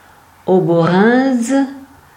Auboranges (French pronunciation: [obɔʁɑ̃ʒ]; Arpitan: Ôborenjos, locally Ouborindze[3] [obɔˈʁɛ̃dzə]
Frp-greverin-Ouborindze.ogg.mp3